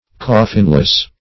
Coffinless \Cof"fin*less\, a. Having no coffin.